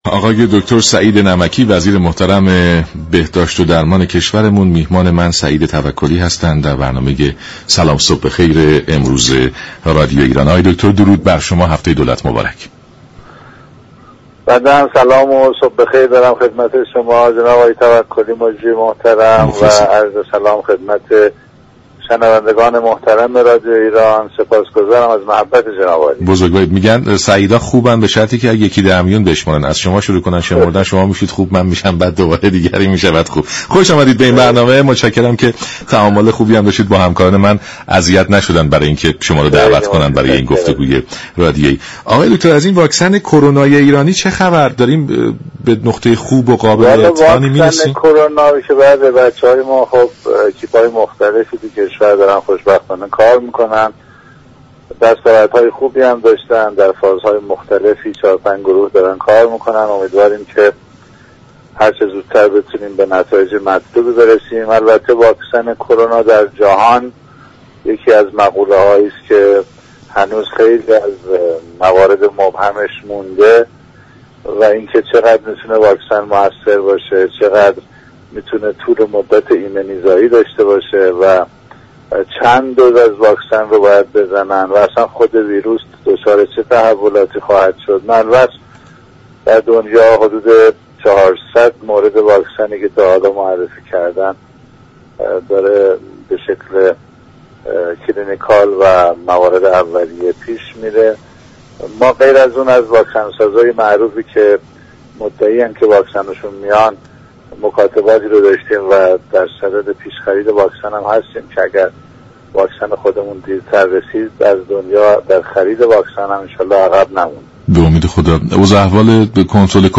دكتر نمكی در ادامه این گفت و گوی رادیویی افزود : كادر پزشكی كشور با لباس هایی كه در حین انجام كار می پوشند و با توجه به شرایط موجود توان لازم را ندارند و این تقاضا را داریم كه همه رعایت كنند. پیش بینی این است كه ممكن است در پاییز اوجی از آنفولانزا و كرونا را با هم داشته باشیم و این شرایط ممكن است درباره كادر پزشكی اشكال ایجاد كند.